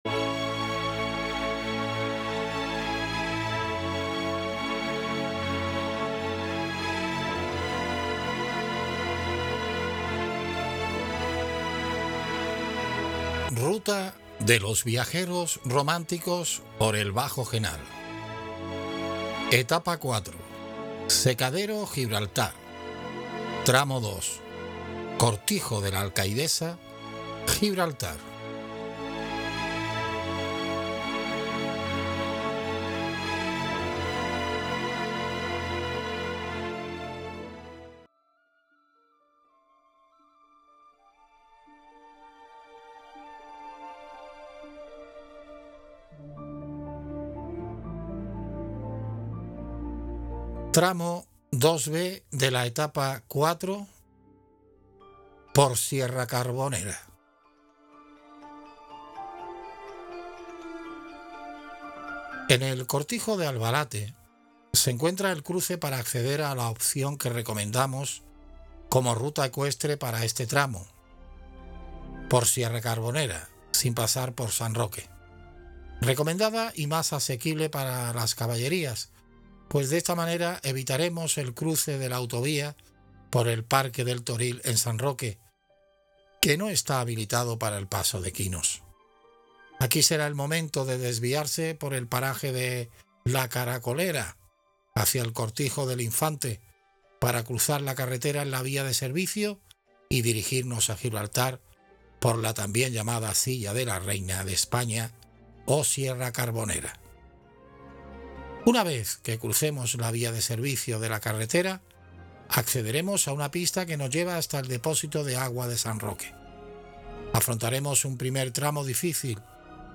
VOCES en OFF: